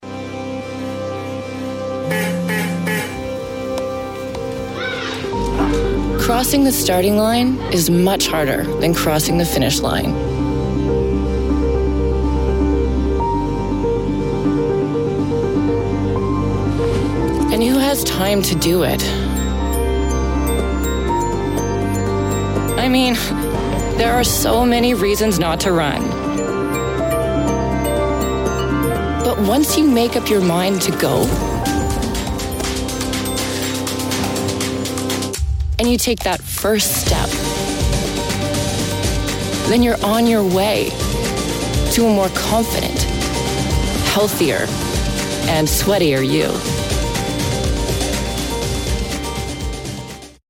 Adidas Supernova Commercial
Canadian
Young Adult